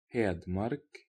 Hedmark (Norwegian: [ˈhêːdmɑrk]